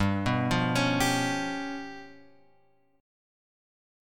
G 7th Flat 5th